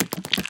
Звуки пауков
Все звуки оригинальные и взяты прямиком из игры.
Передвижение/Шаги №2
SpiderStep2.mp3